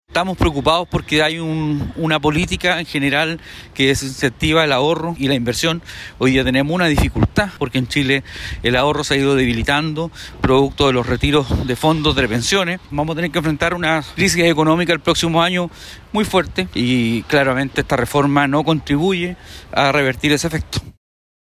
El diputado de Renovación Nacional, Frank Sauerbaum, destacó la apertura que ha ido mostrando el Ejecutivo, que señaló que iba a estudiar esta materia.